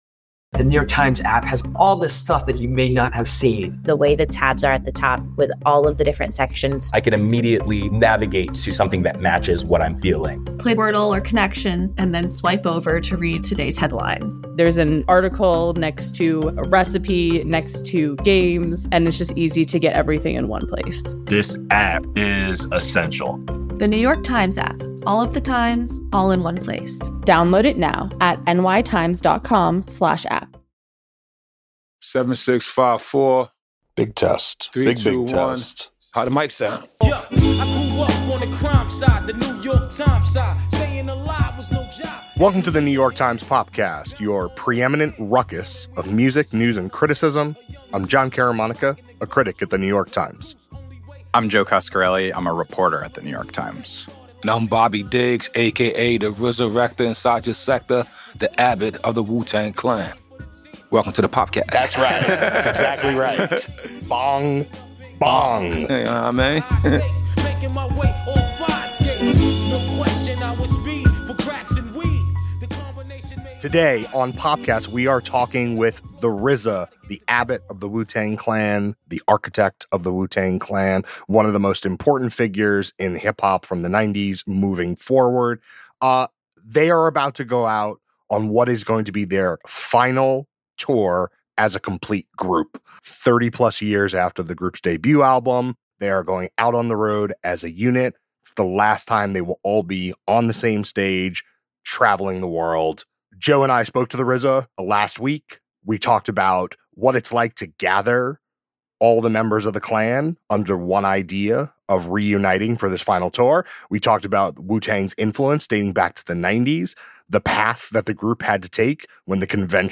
RZA Interview! How He Got Wu-Tang Clan Back Together for a Final Tour